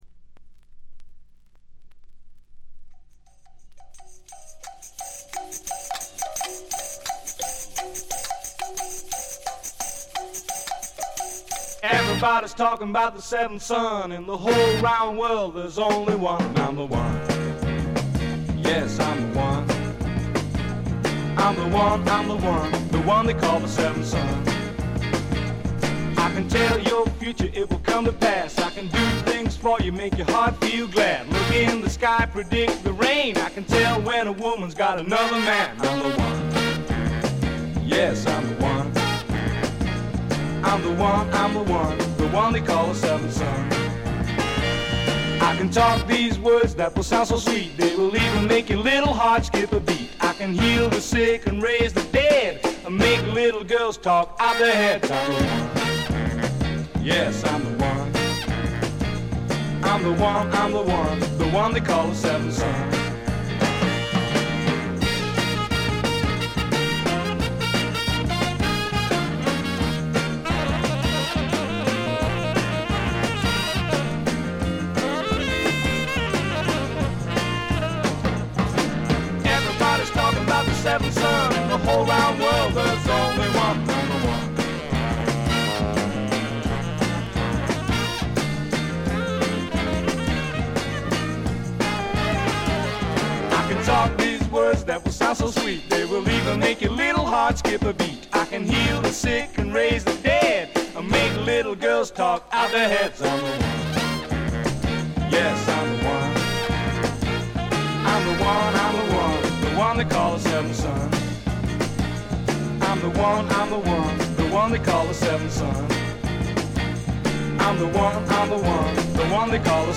わずかなチリプチ。散発的なプツ音が数回。
試聴曲は現品からの取り込み音源です。